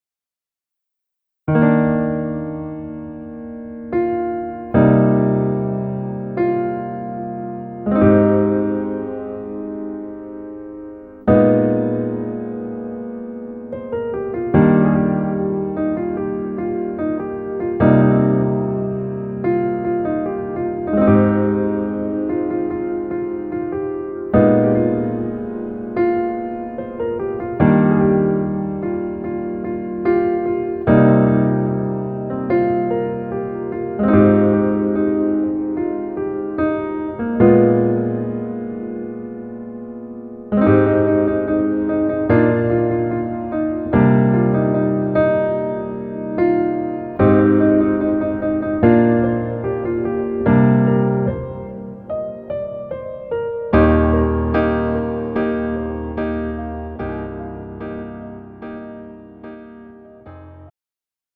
음정 -1키 3:33
장르 가요 구분 Pro MR